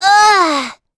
Gremory-Vox_Damage_02.wav